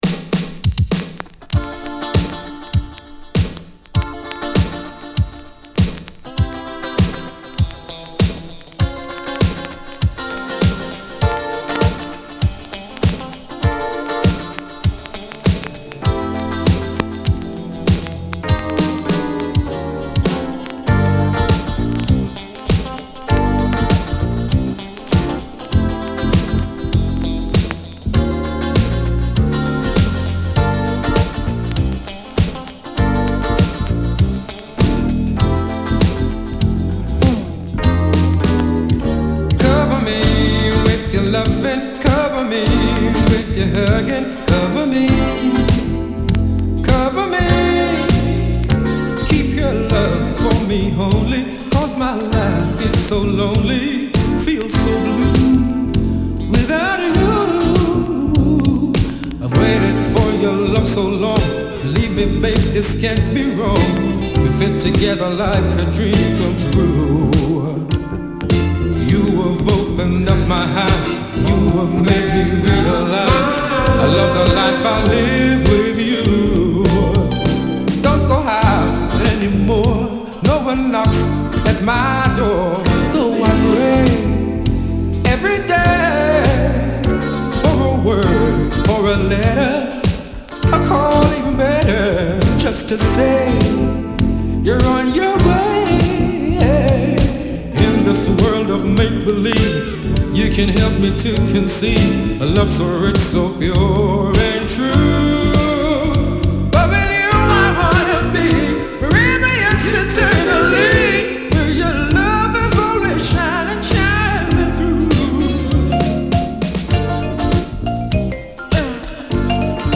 UK Modern Dancer!!
※傷がありノイズが気になる箇所あり。B-1頭で特にノイズが気になります。
※この盤からの録音ですので「試聴ファイル」にてご確認下さい。